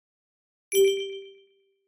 Звуковые эффекты iMac и MacBook